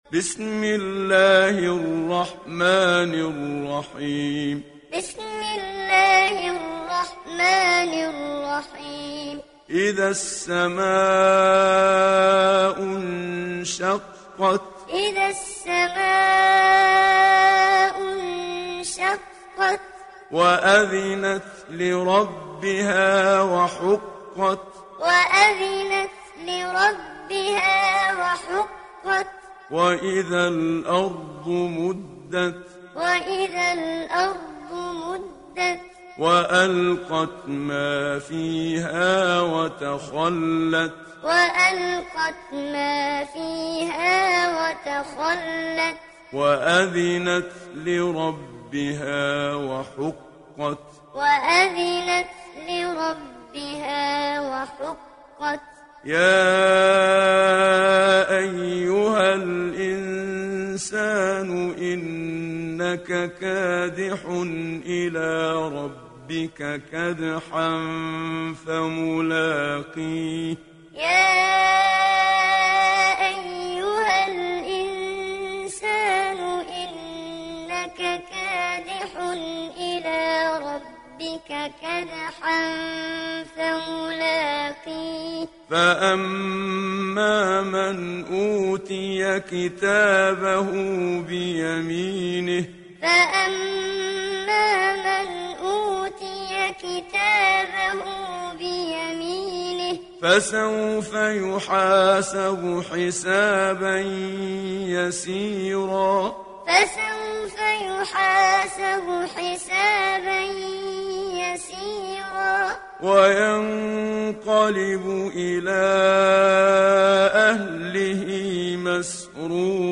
دانلود سوره الانشقاق محمد صديق المنشاوي معلم